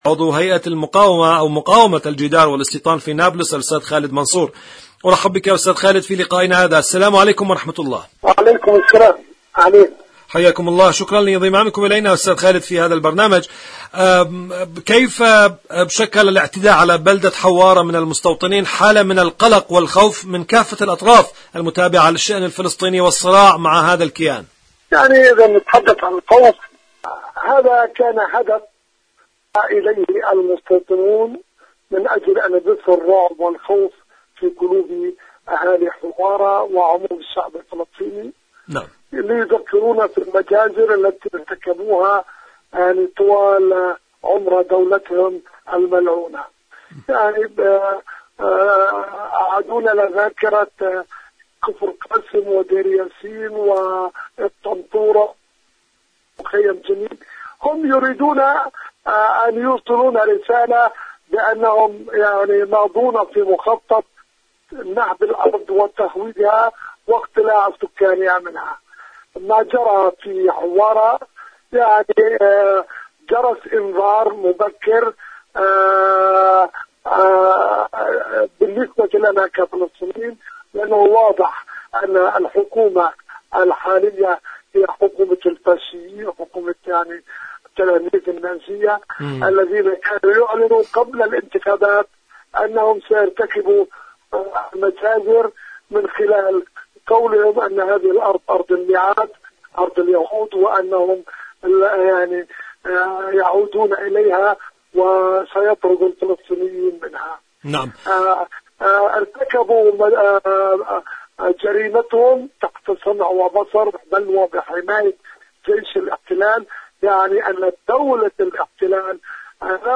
مقابلات إذاعية برنامج فلسطين اليوم